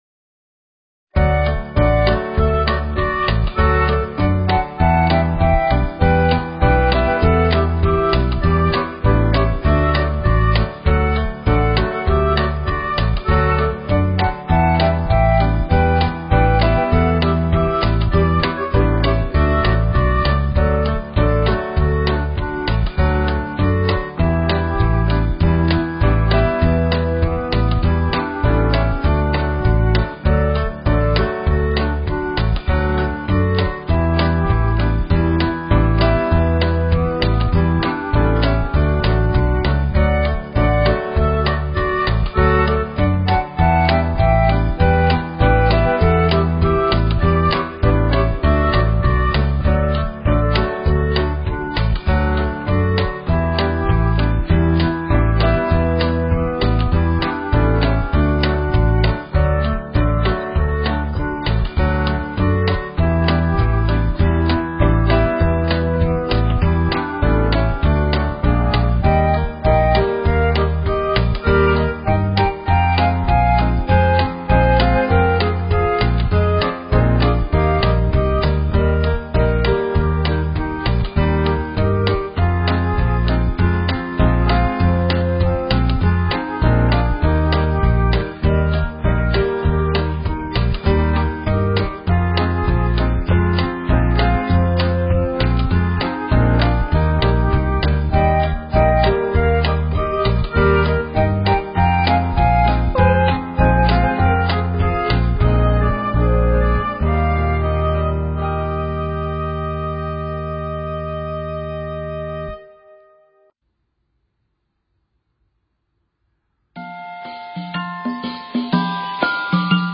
英語歌唱比賽音檔-無人聲合併版
401英語歌唱比賽無人聲音檔.m4a